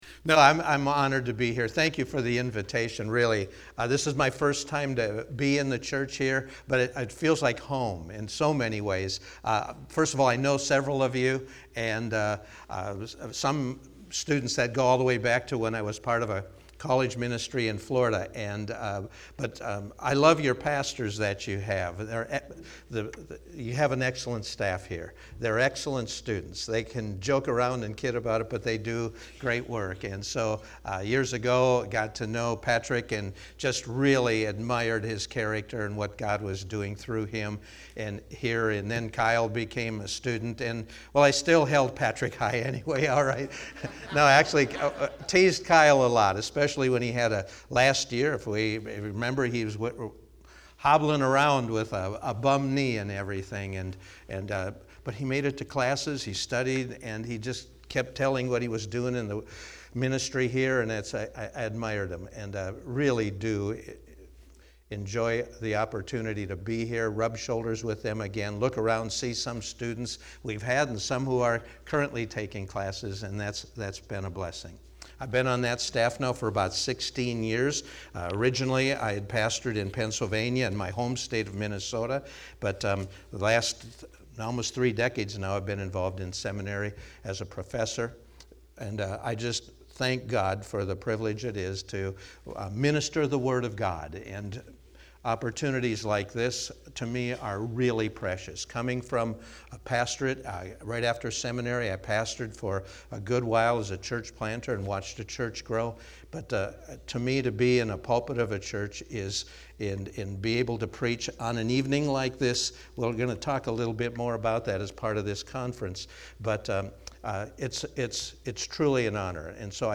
Fall Bible Conference